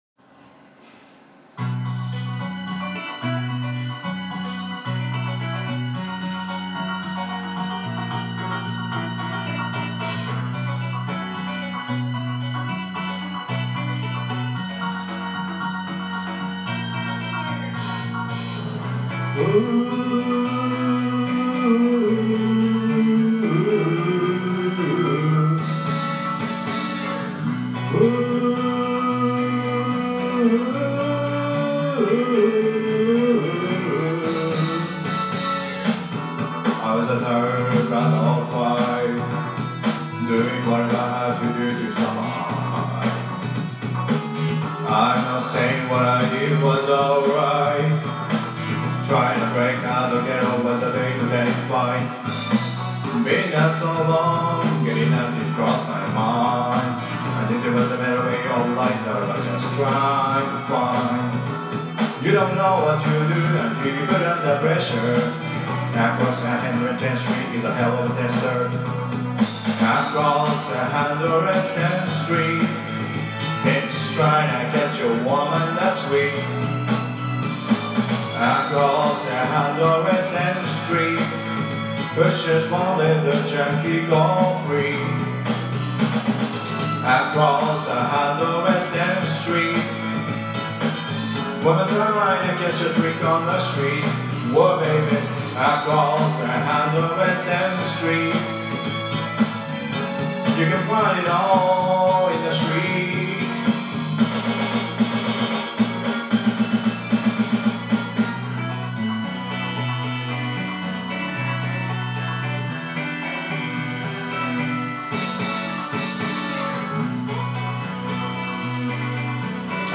Karaoke version.